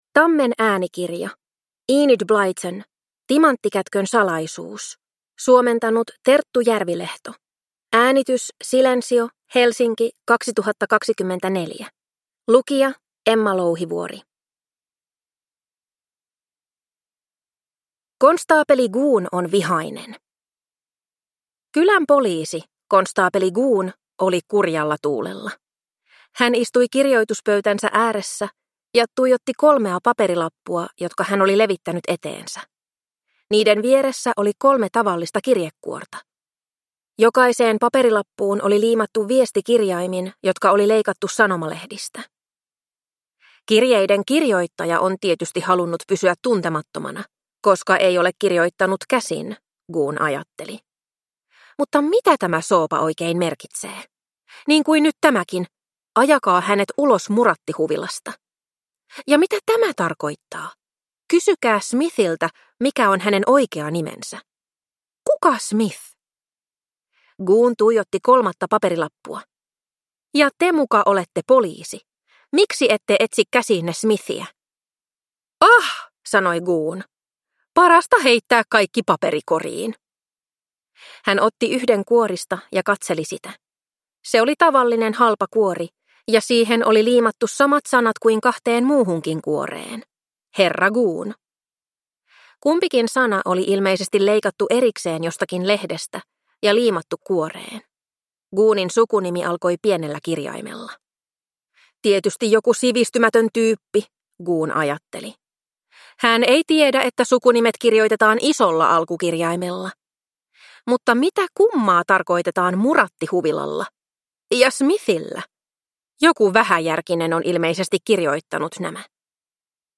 Timanttikätkön salaisuus – Ljudbok